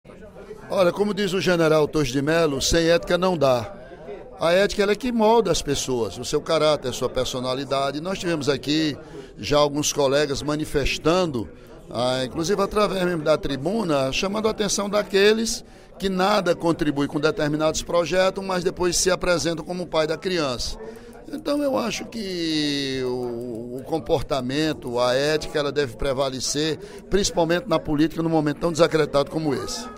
O deputado Ely Aguiar (PSDC) criticou, durante o primeiro expediente da sessão plenária desta terça-feira (21/02), políticos que, segundo ele, estariam querendo se apropriar do projeto de construção da imagem de Nossa Senhora de Fátima, no Crato. O parlamentar informou que foi o único deputado que trabalhou para viabilizar a obra.